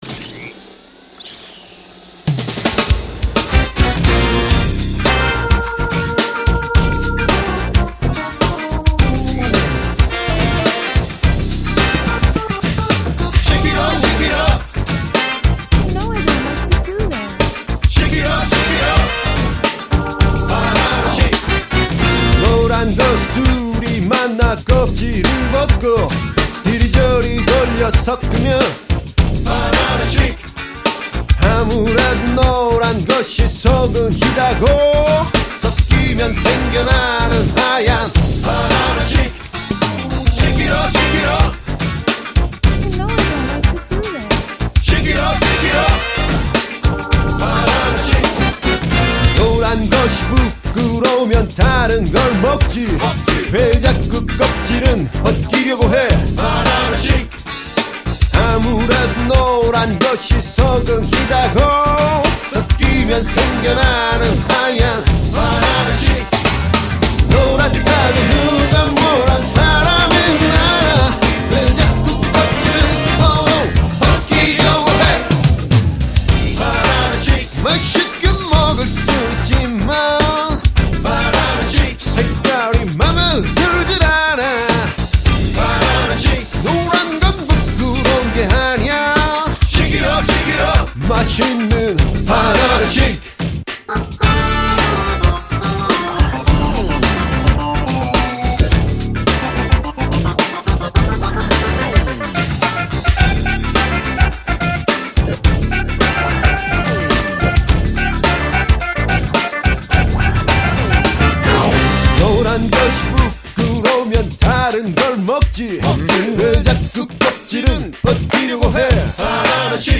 보컬, 기타
드럼
펑키한 리듬과 연주가 보컬과 잘 맞아 떨어진다고 생각했고